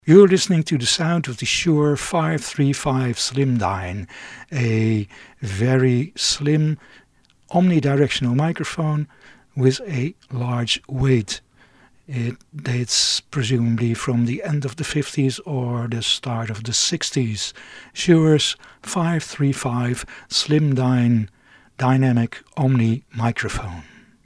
Shure realised the need for a handheld type and presented the 'Slendyne' 530 omni directional probe microphone, for PA and broadcast, in 1955, priced $110.
Shure 535 Sound UK.mp3